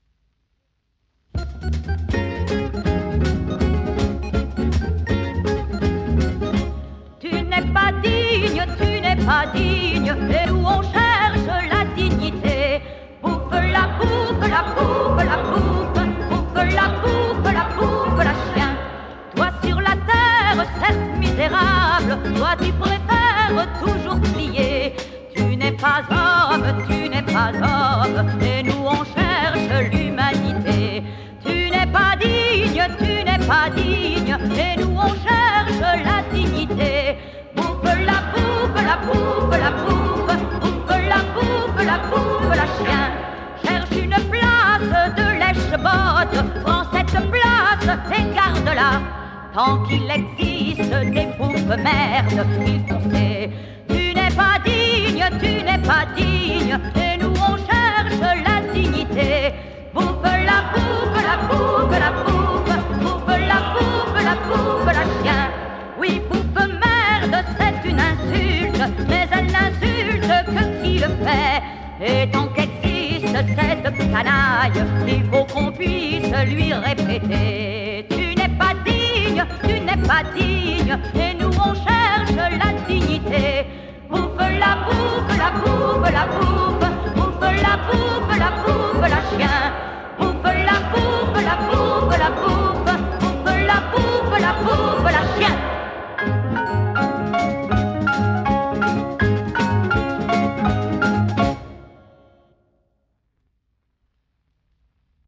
Enregistré au Théâtre Gérard Philipe de St-Denis